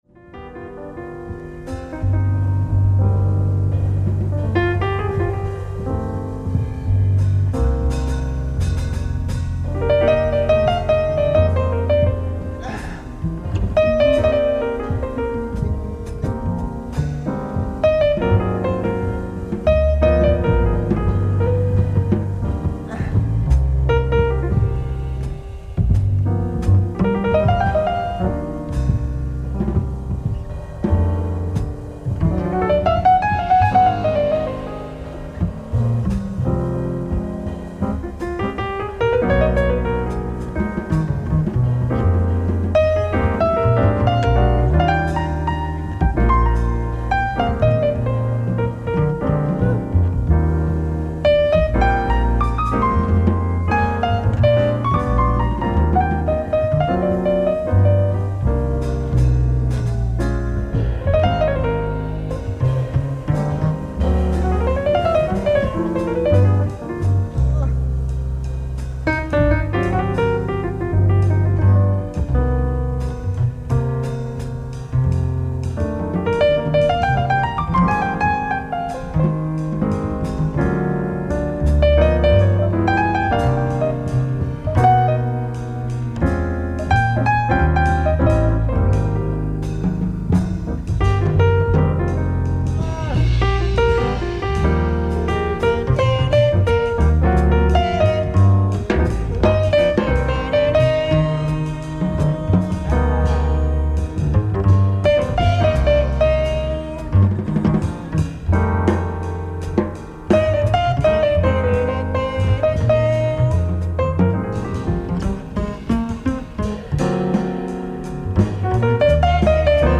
ライブ・アット・アンティーブ・ジャズ、ジュアン・レ・パン、フランス 07/25/1992
※試聴用に実際より音質を落としています。